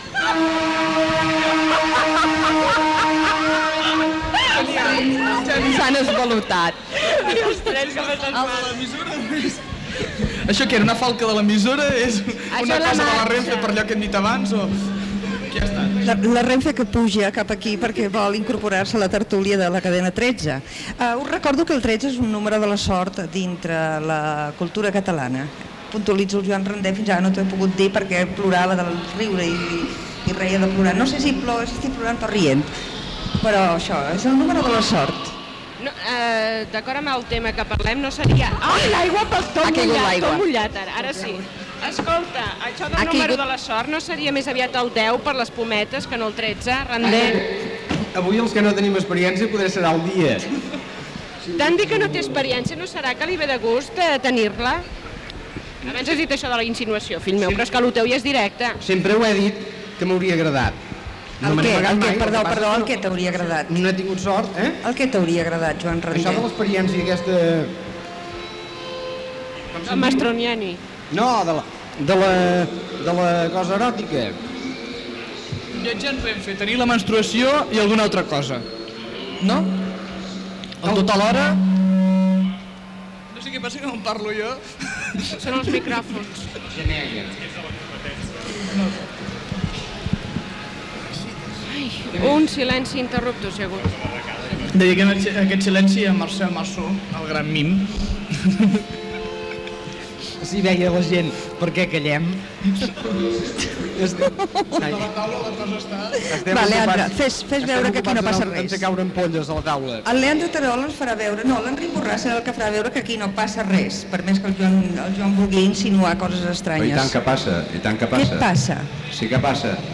Tertúlia feta a l'estació de França, amb motiu de la VII Setmana del llibre en català